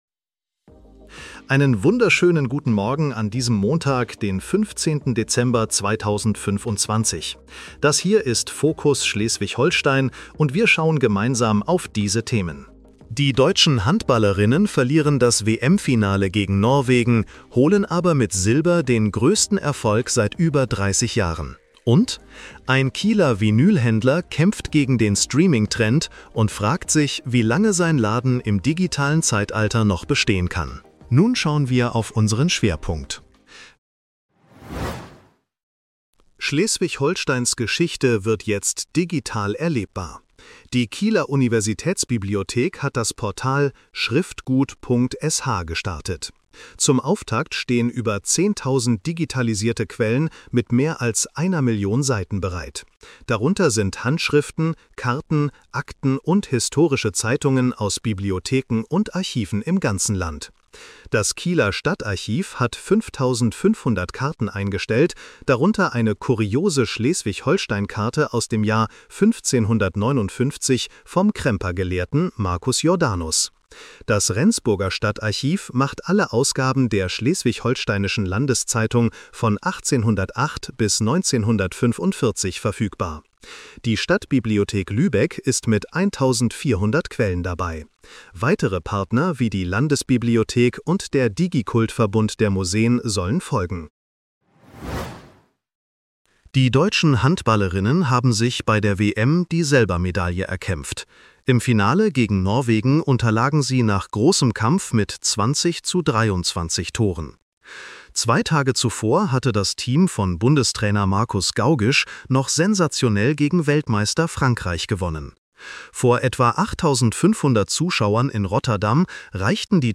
Nachrichten-Podcast bekommst Du ab 7:30 Uhr die wichtigsten Infos